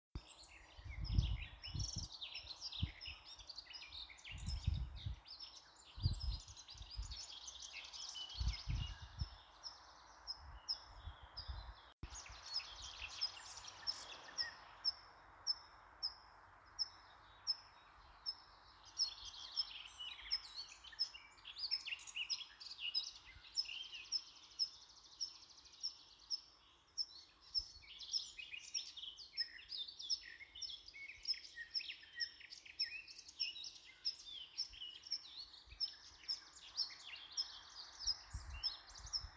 лесной конек, Anthus trivialis
СтатусСлышен голос, крики
ПримечанияDzirdēts krūmājā netālu no ezera.